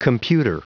Computer.wav